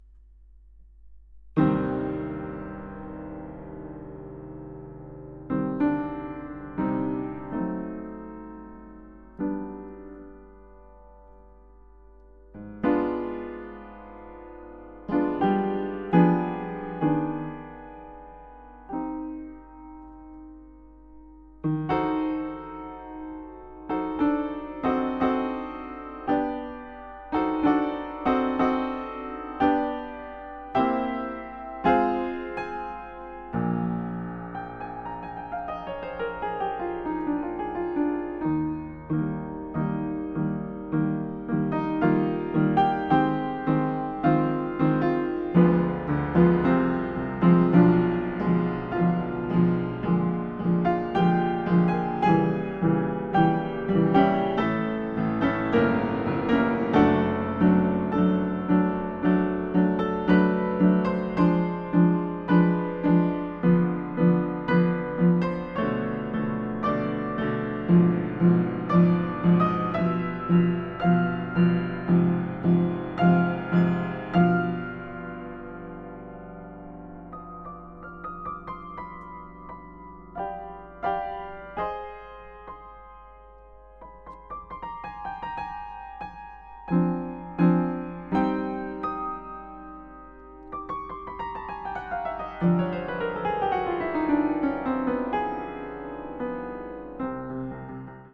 My main instrument is the piano.